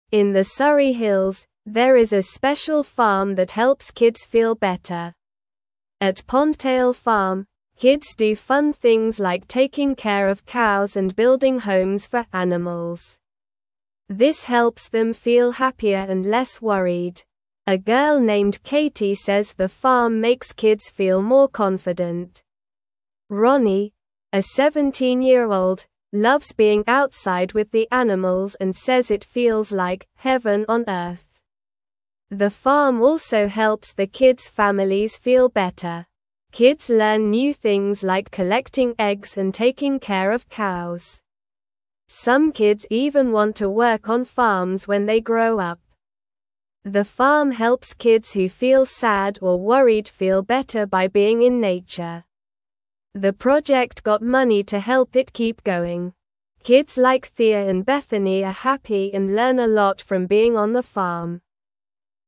Slow
English-Newsroom-Beginner-SLOW-Reading-Happy-Kids-at-Pondtail-Farm-with-Animals.mp3